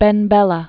(bĕn bĕlə), Ahmed 1918–2012.